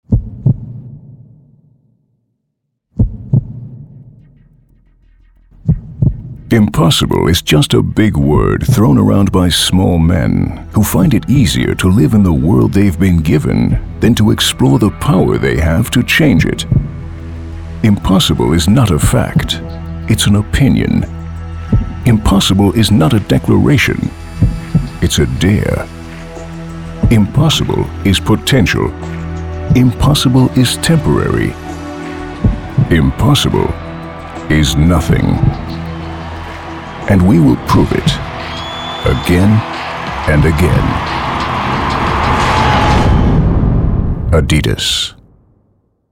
amerikanischer Profi-Sprecher.
Sprechprobe: Sonstiges (Muttersprache):
english (us) voice over talent.